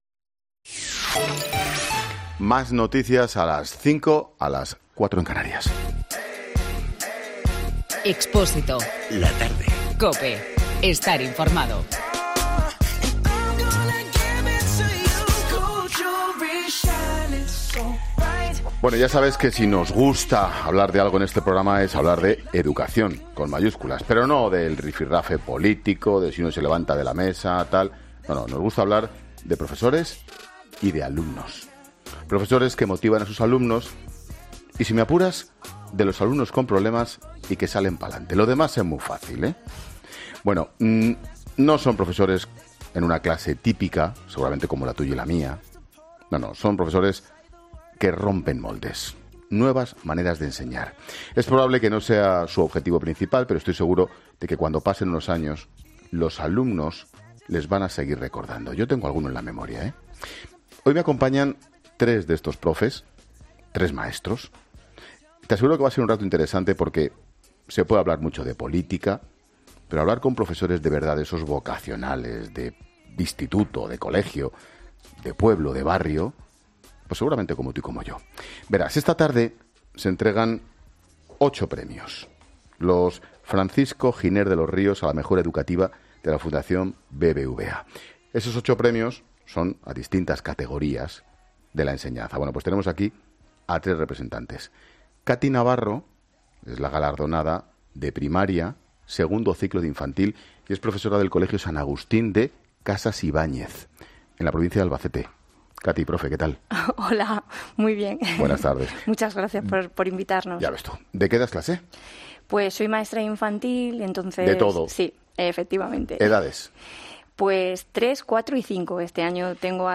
Hablamos en 'La Tarde' con tres de los profesores que han sido premiados por su "particular" trabajo en las aulas